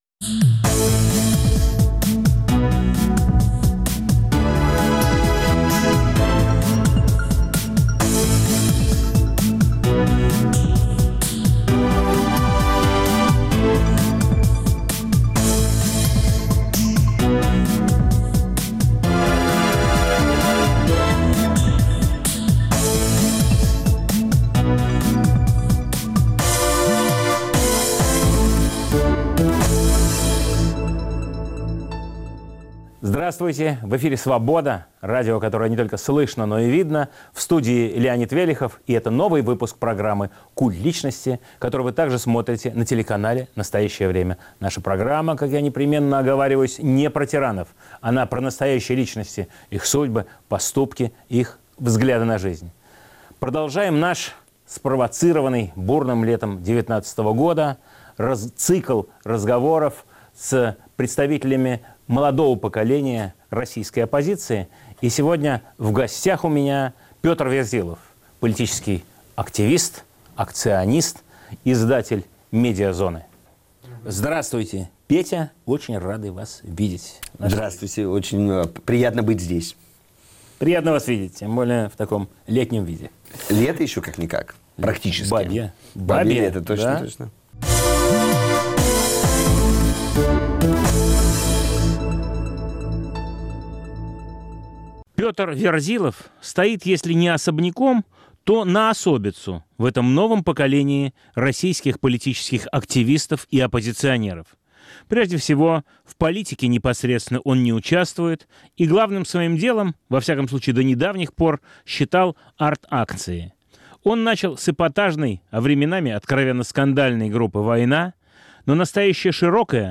Гость передачи – известный акционист, политический активист, издатель «Медиазоны» Петр Верзилов.